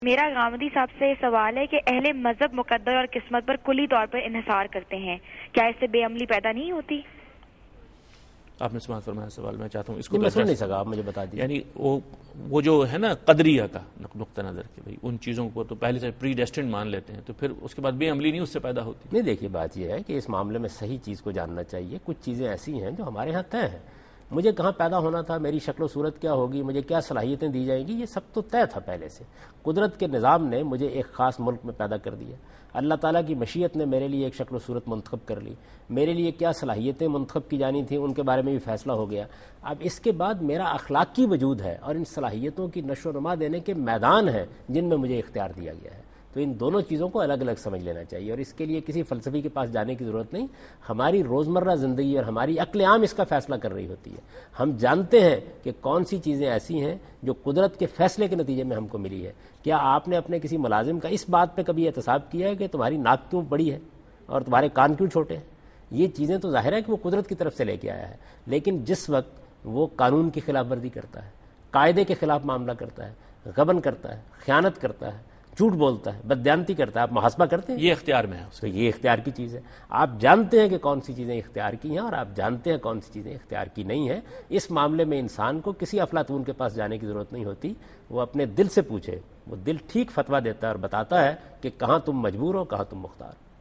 Category: TV Programs / Dunya News / Deen-o-Daanish / Questions_Answers /
دنیا نیوز کے پروگرام دین و دانش میں جاوید احمد غامدی ”شیطان اور خواتین کا میک اپ“ سے متعلق ایک سوال کا جواب دے رہے ہیں